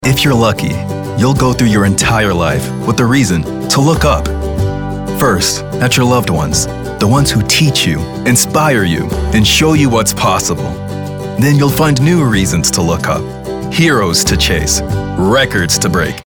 Their upbeat, friendly vibe brings scripts to life! they nail commercials, audiobooks, and animation with authentic charm.
anti-announcer, concerned, confessional, conversational, friendly, genuine, Gravitas, guy-next-door, informative, mellow, millennial, motivational, serious, sincere, thoughtful, warm